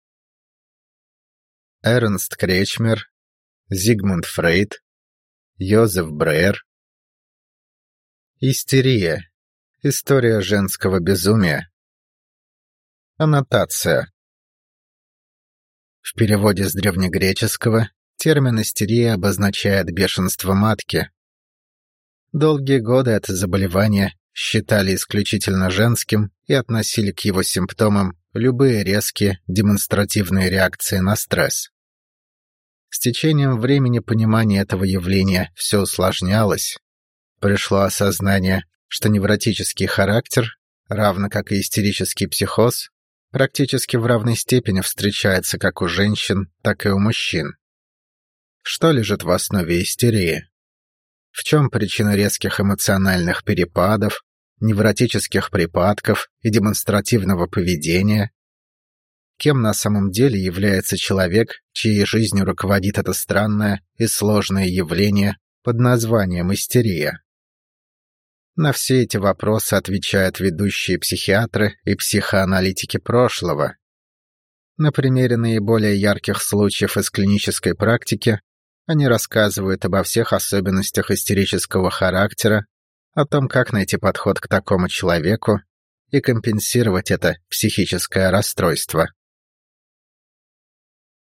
Aудиокнига Истерия.